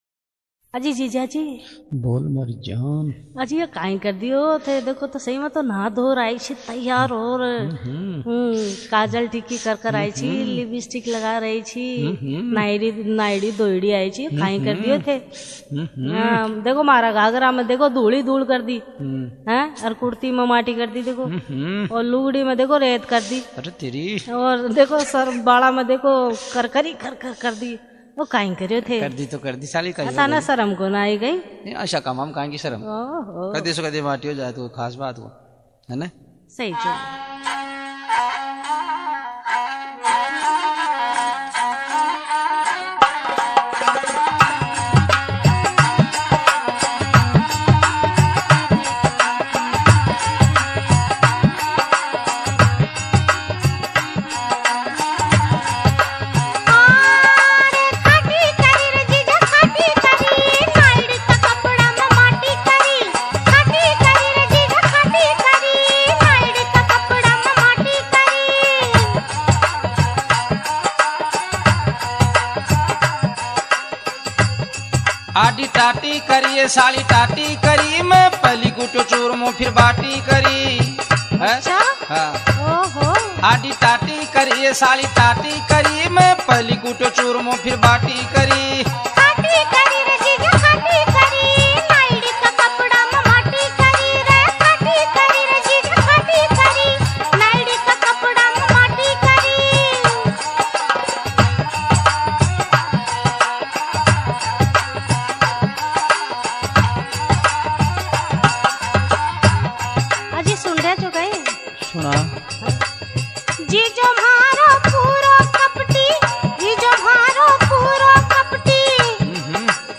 Rajasthani Songs